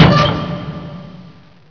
doorclose2.wav